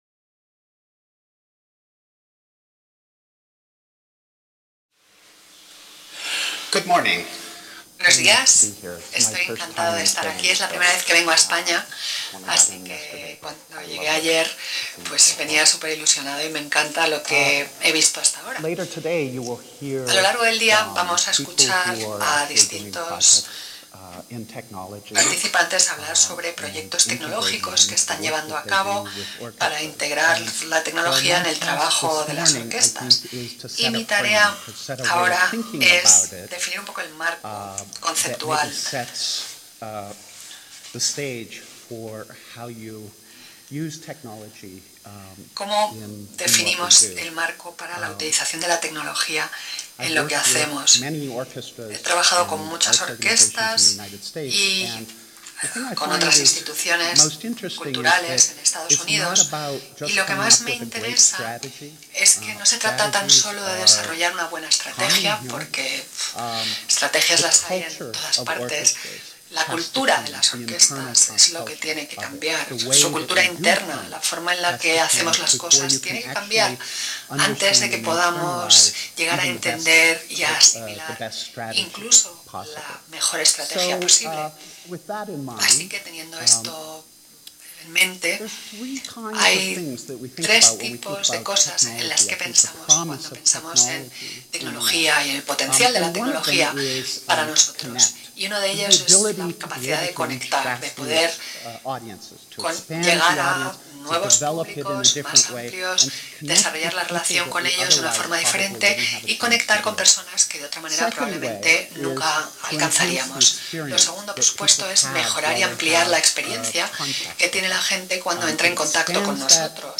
Ponencia inaugural Opening speech